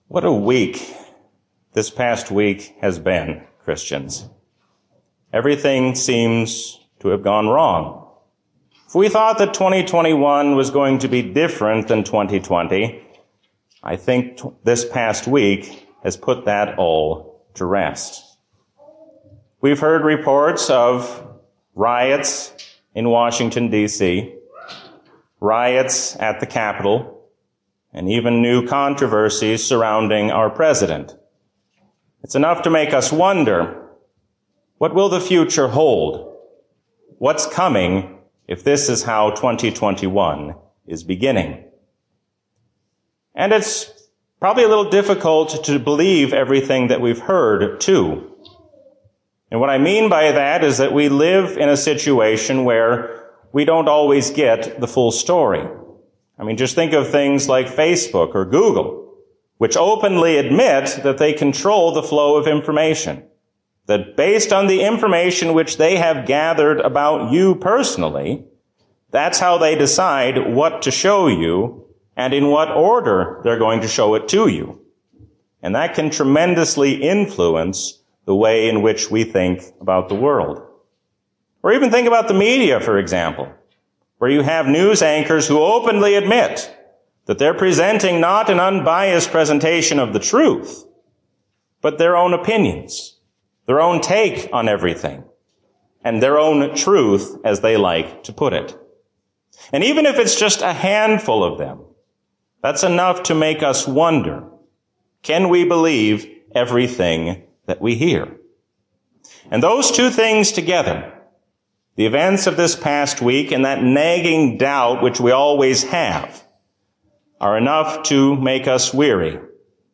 A sermon from the season "Trinity 2021." The world cannot help us, but God will give us a future greater than we can imagine.